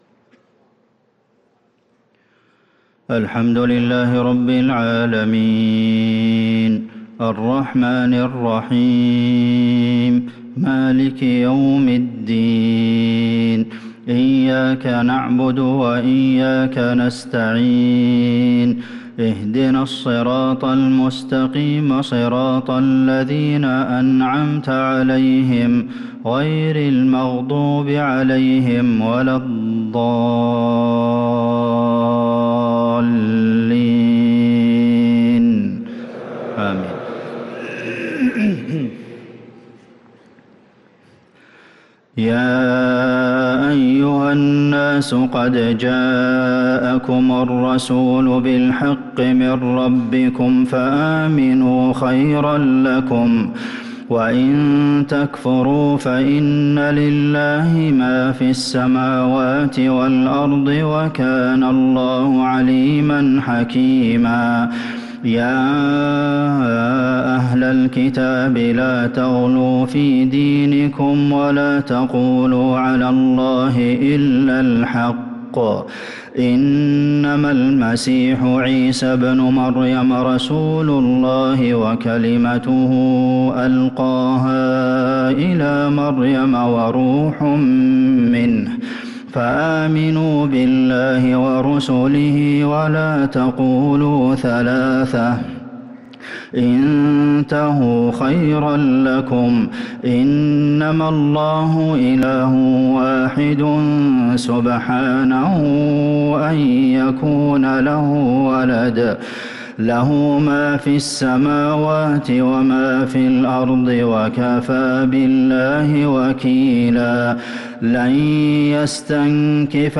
صلاة العشاء للقارئ عبدالمحسن القاسم 24 جمادي الآخر 1445 هـ
تِلَاوَات الْحَرَمَيْن .